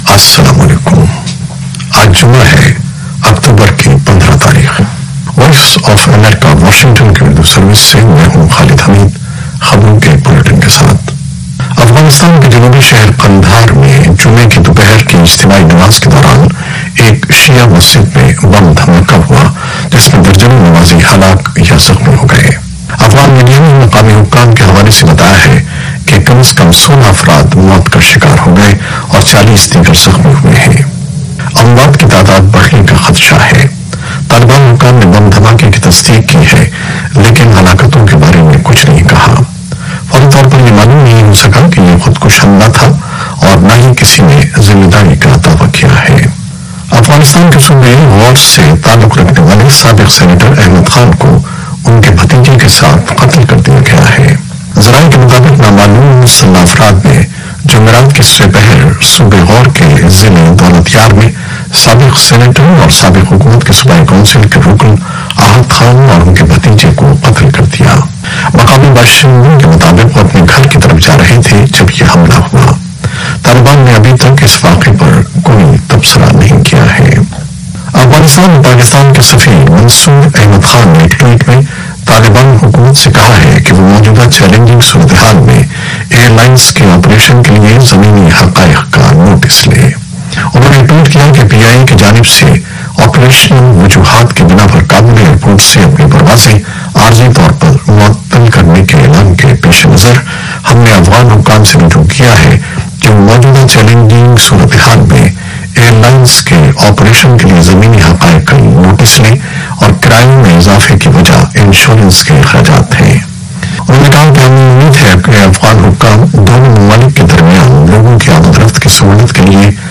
نیوز بلیٹن 2021-15-10